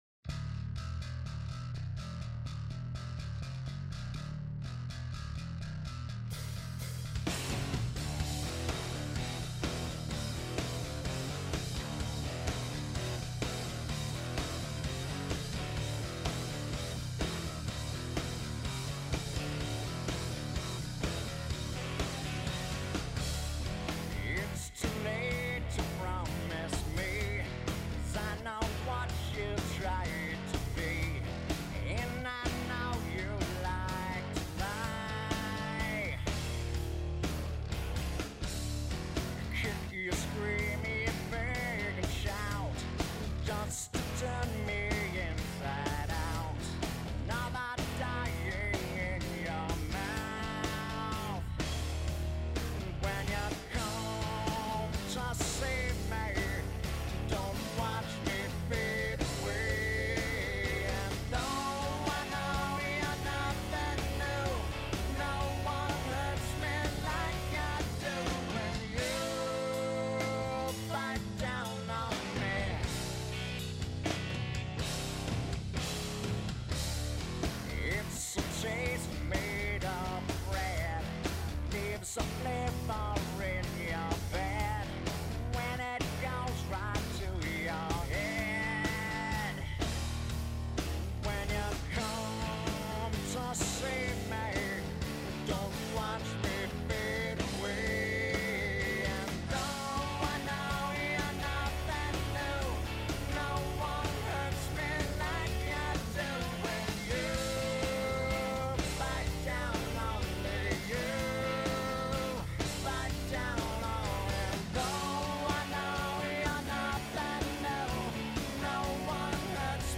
heavy rock tunes
baritone guitar
bass, spoken word